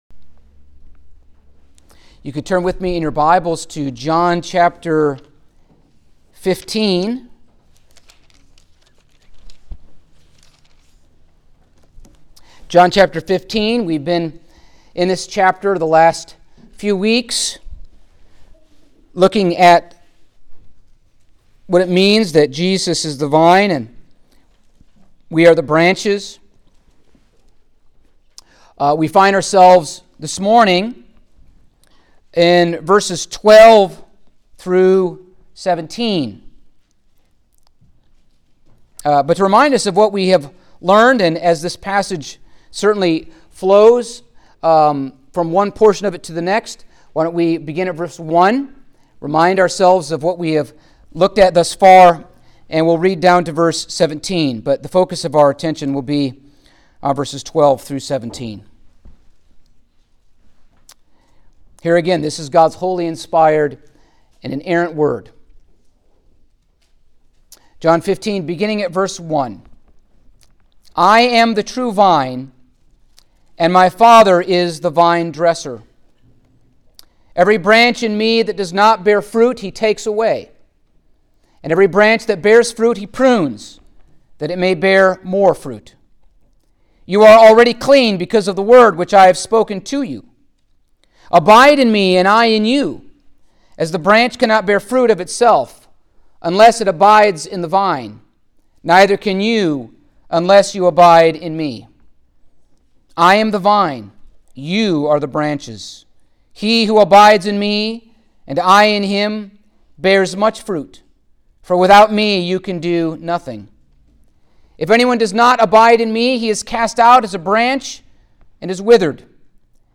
Passage: John 15:12-17 Service Type: Sunday Morning